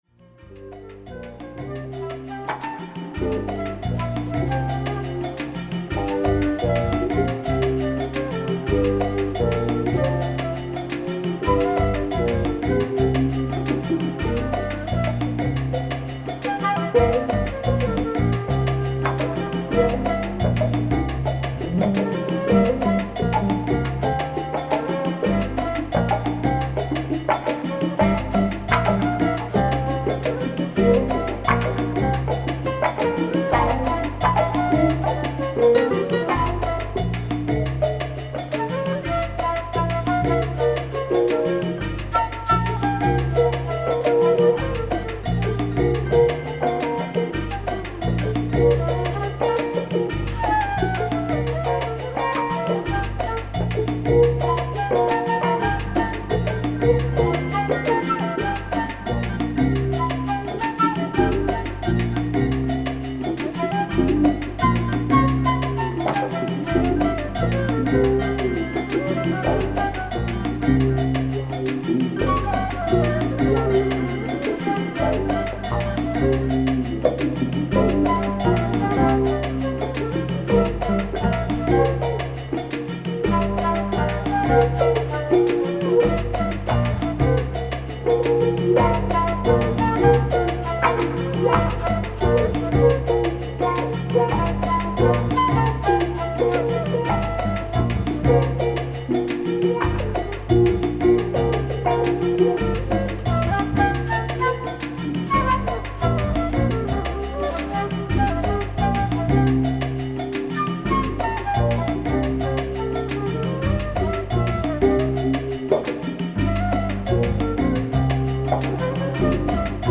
Tempo: 85 bpm / Date: 09.07.2018